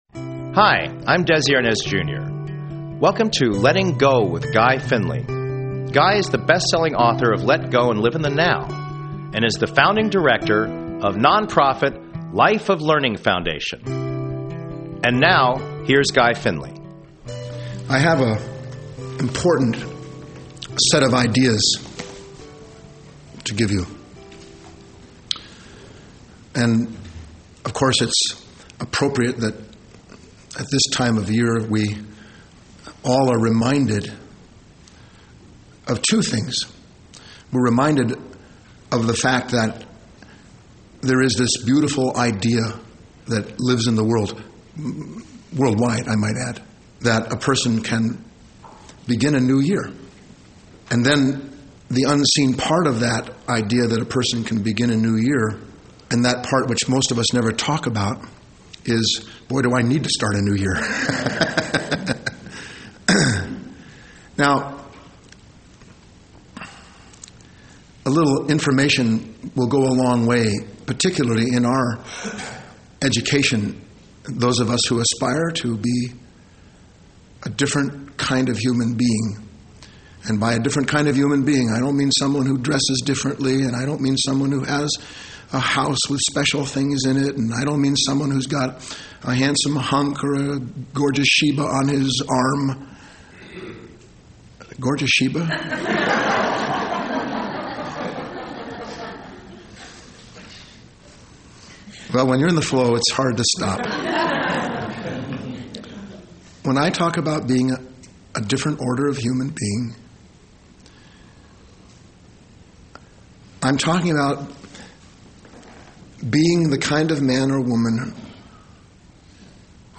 Talk Show Episode, Audio Podcast, Letting_Go_with_Guy_Finley and Courtesy of BBS Radio on , show guests , about , categorized as